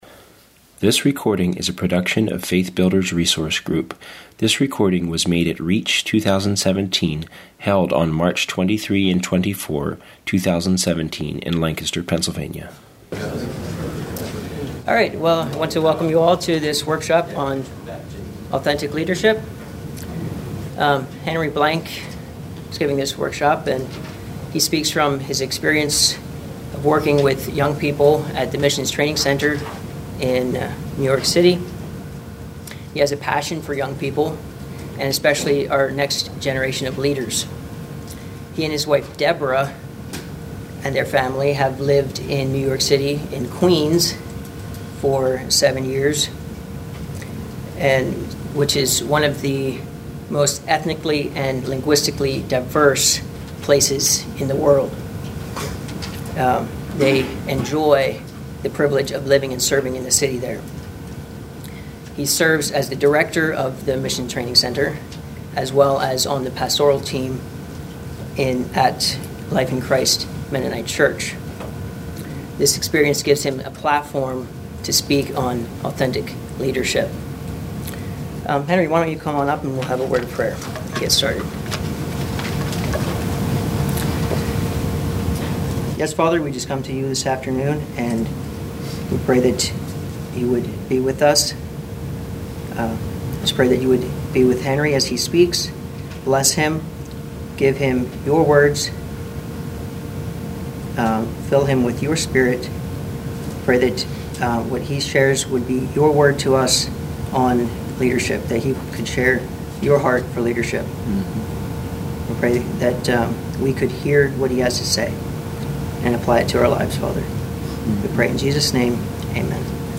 Home » Lectures » Authentic Leadership